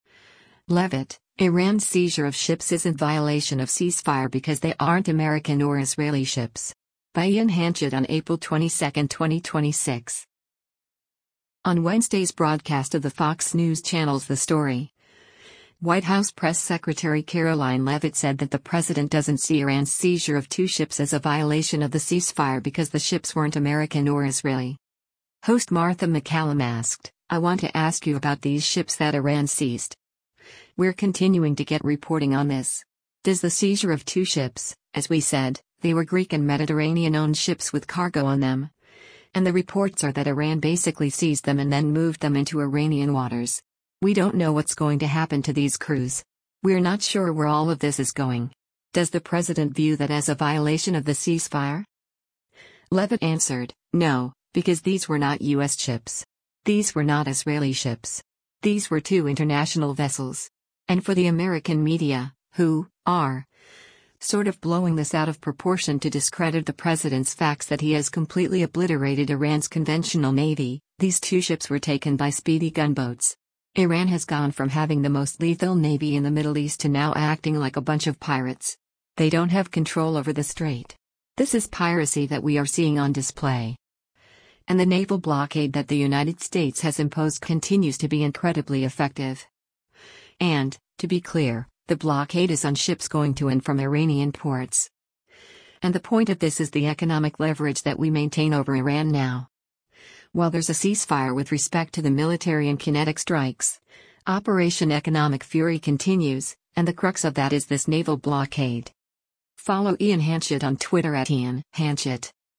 On Wednesday’s broadcast of the Fox News Channel’s “The Story,” White House Press Secretary Karoline Leavitt said that the president doesn’t see Iran’s seizure of two ships as a violation of the ceasefire because the ships weren’t American or Israeli.